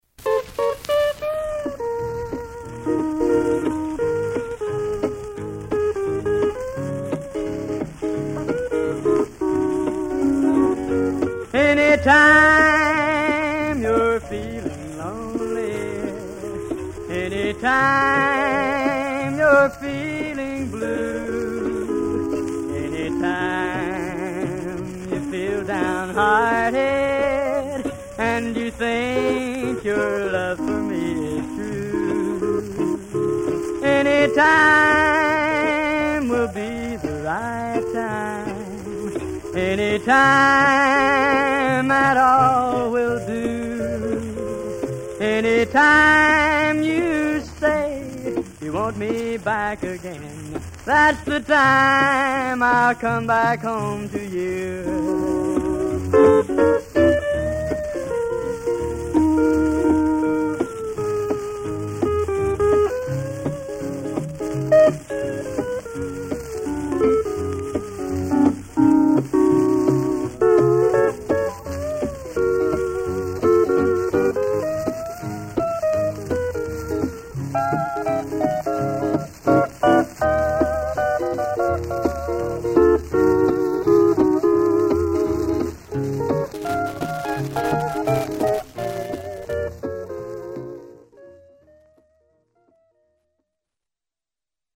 Years ago when I bought a Fender 1000, I retired my "road-weary" Custom T-8, and then spent an entire winter carefully refinishing it.
The folowing clip was played on this guitar during it's "active" life 47 years ago: